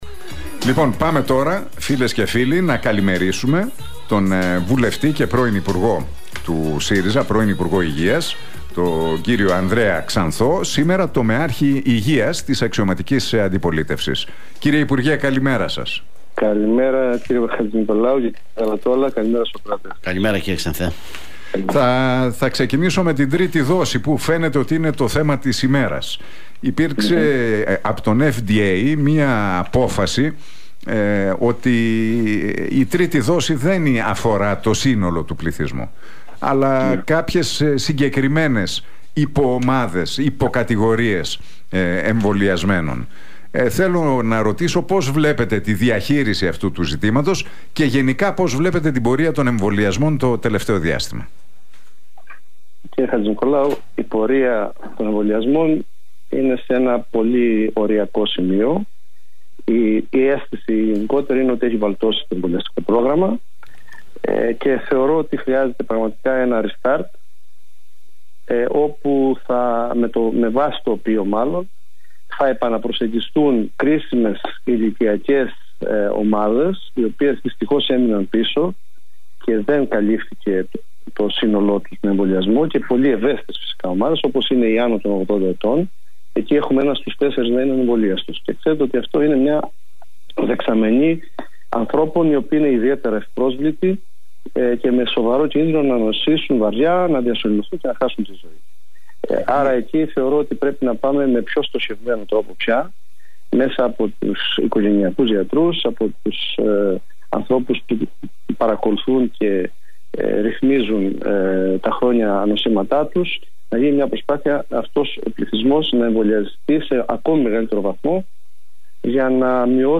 Ο βουλευτής του ΣΥΡΙΖΑ και τομεάρχης Υγείας του κόμματος, Ανδρέας Ξανθός, σε συνέντευξή του στον Realfm 97,8 αναφέρθηκε στους εμβολιασμούς και στην τρίτη δόση.